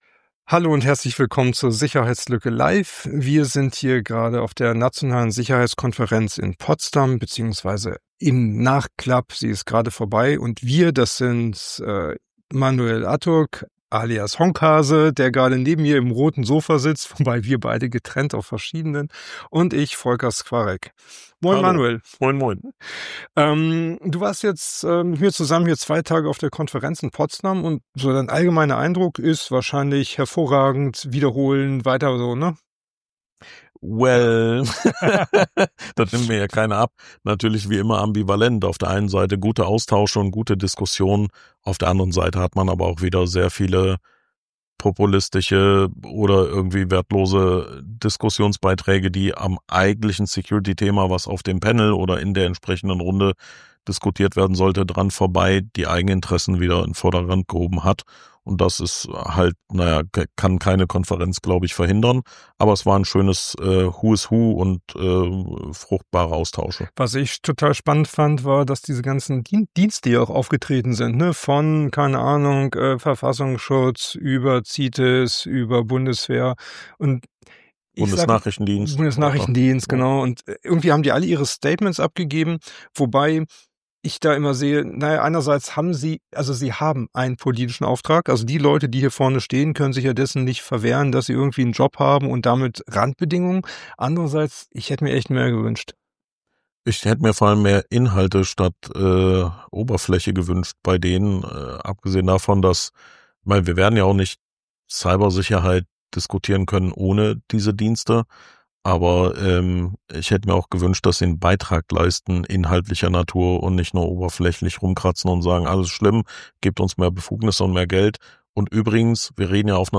Die Sicherheits_lücke Live von der Konferenz für Nationale Cybersicherheit ~ Die Sicherheits_lücke Podcast